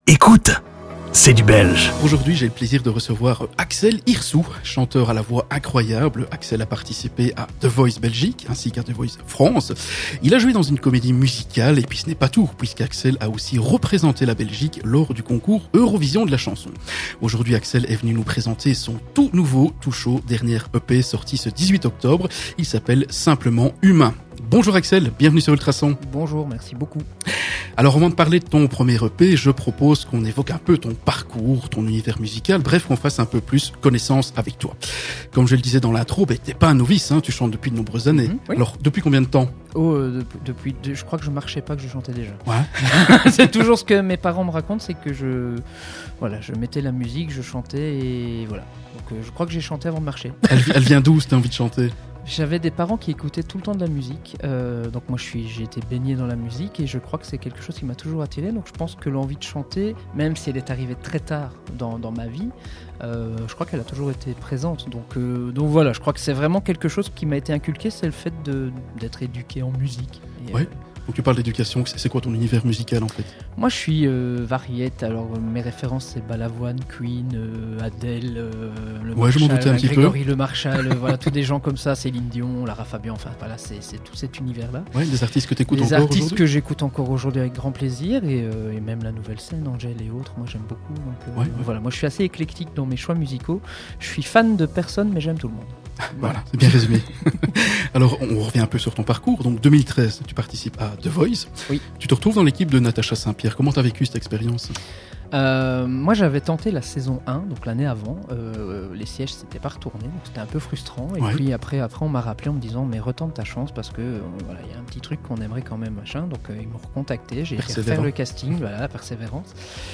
Durant cette interview, nous avons évoqué son parcours artistique, ses inspirations, sa musique, les difficultés rencontrées, son actualité, ses projets, ses rêves et bien d'autres choses...